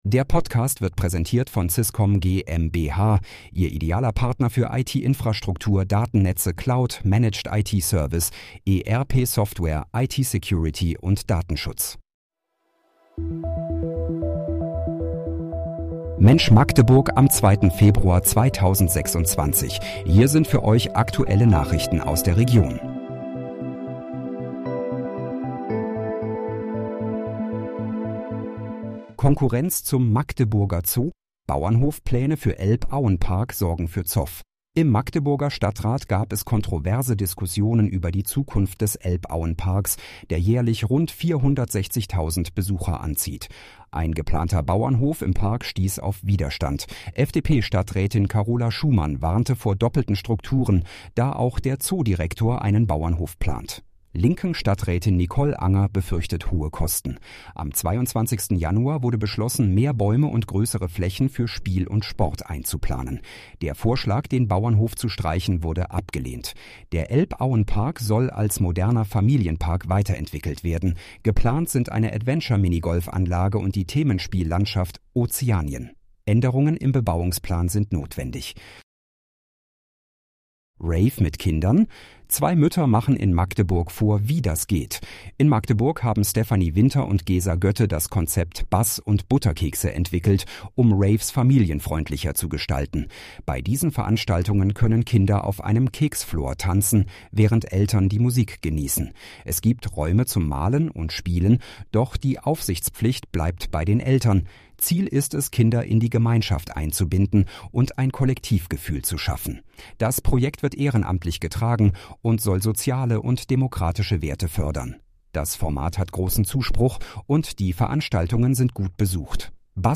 Mensch, Magdeburg: Aktuelle Nachrichten vom 02.02.2026, erstellt mit KI-Unterstützung
Nachrichten